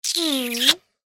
Download Kissing sound effect for free.
Kissing